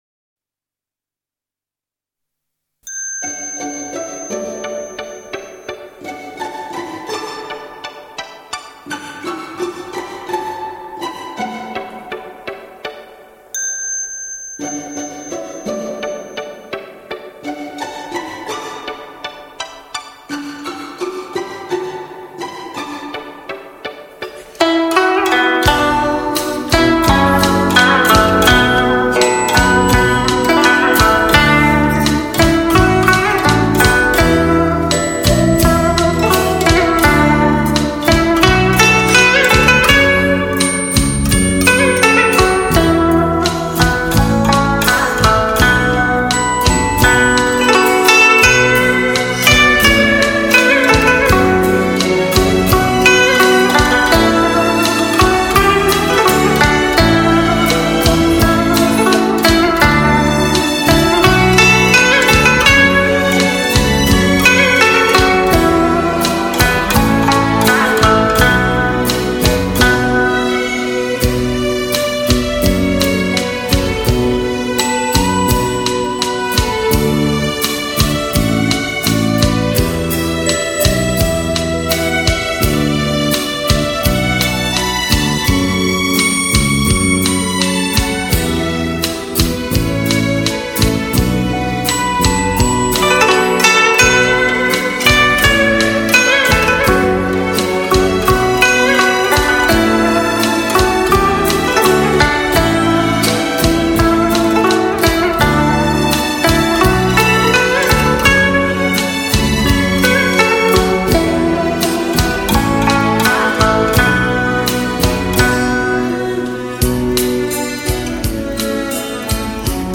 古筝
专辑格式：DTS-CD-5.1声道
为低音质MP3